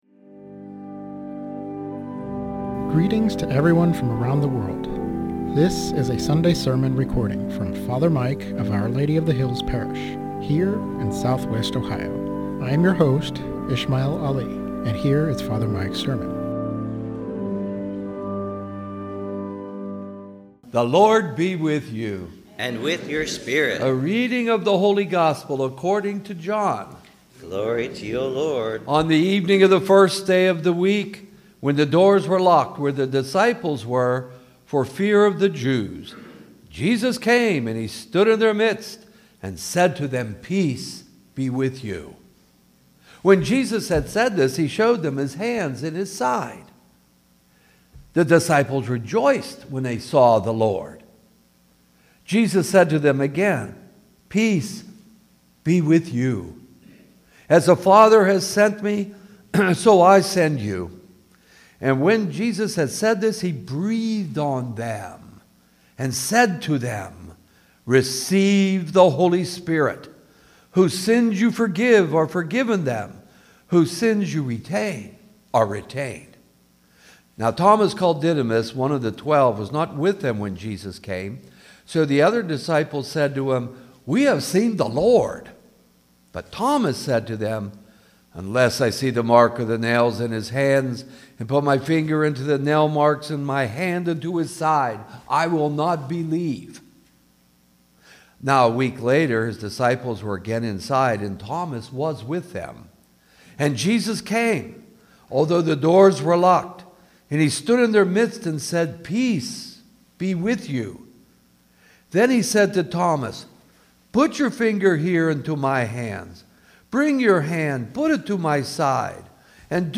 Sermon on John 20:19-31 - Our Lady of the Hills - Church